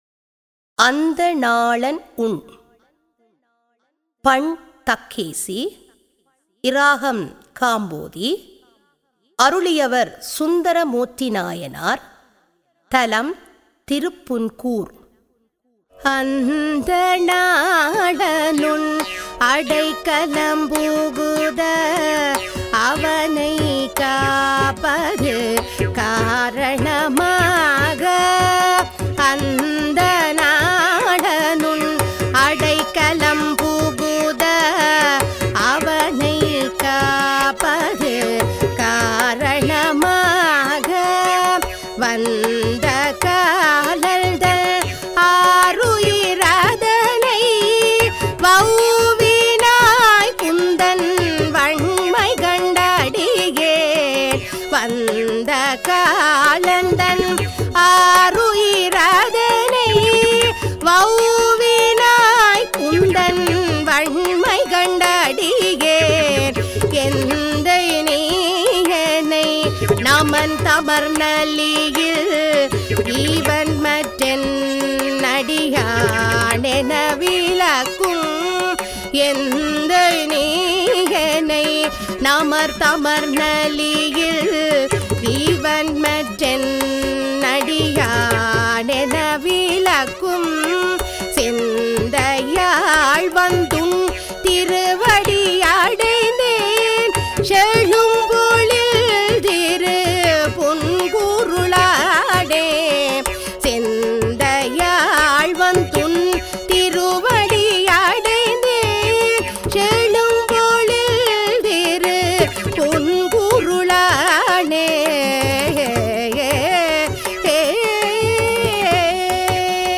தரம் 10 - சைவநெறி - அனைத்து தேவாரங்களின் தொகுப்பு - இசைவடிவில்